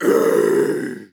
Metal Scream 2 Sample
Categories: Vocals Tags: dry, english, fill, male, Metal, sample, Scream, Tension
TEN-vocal-fills-100BPM-A-3.wav